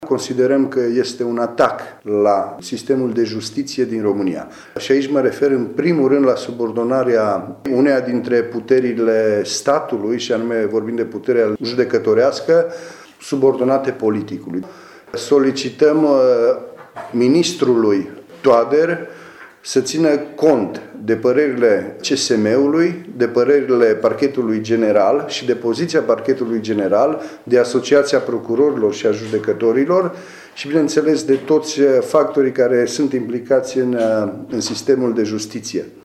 Declarația a fost făcută astăzi, la Tîrgu-Mureș, de către liderul filialei mureșene a formațiunii, senatorul Cristian Chirteș: